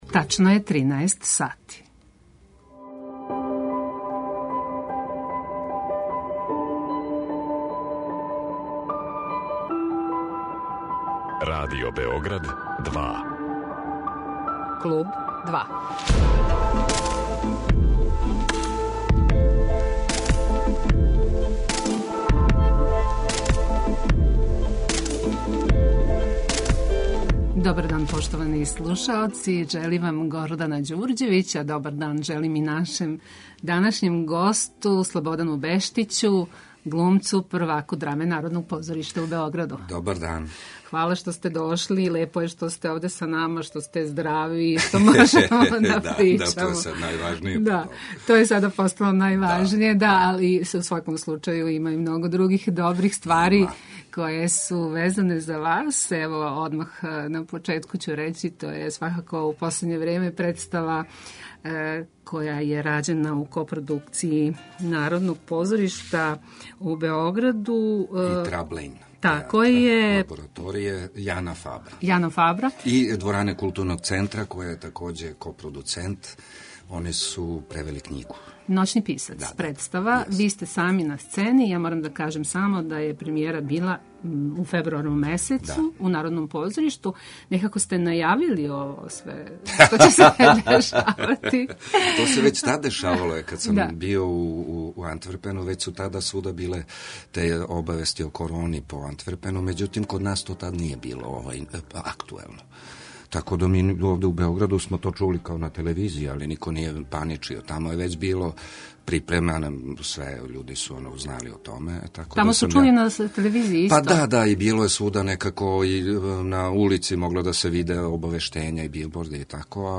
Гост Клуба 2 је глумац Слободан Бештић, првак Драме Народног позоришта у Београду.